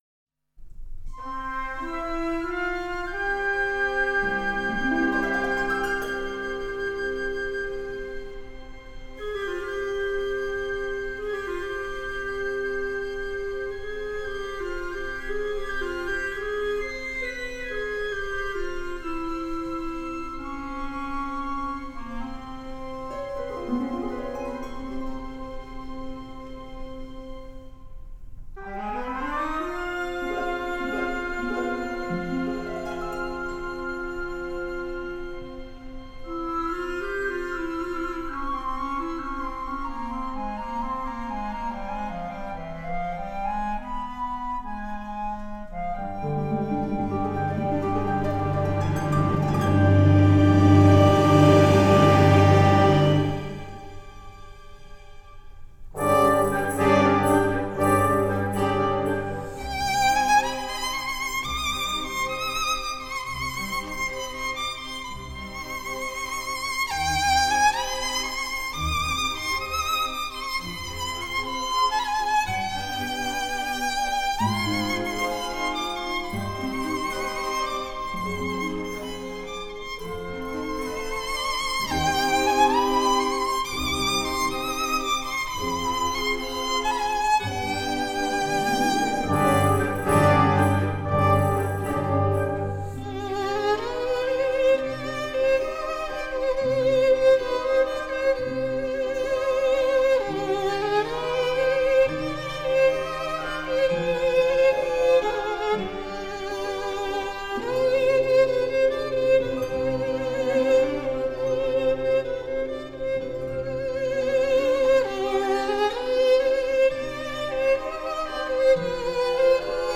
中国管弦乐及小品
、小提琴与管弦乐团作品《黎家节日》